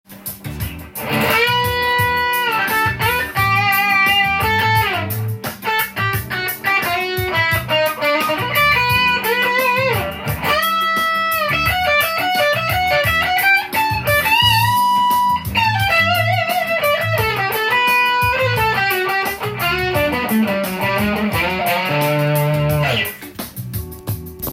カラっとした音の中に芯があり、前に出てくる感じがしました。バンドで使うと音が抜けそうです。